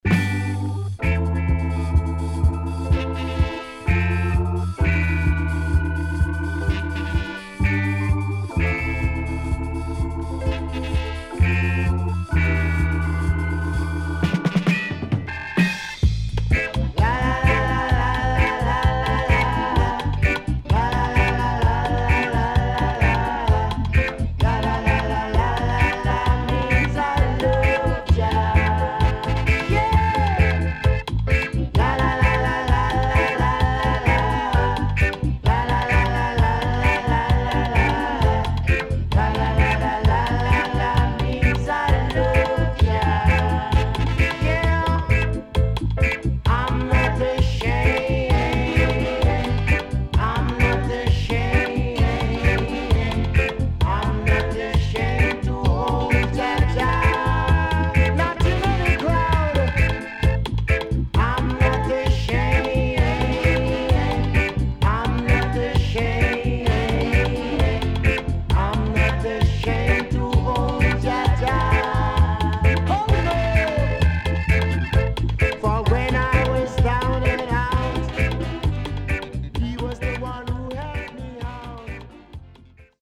SIDE A:少しジリジリしたノイズ入りますが良好です。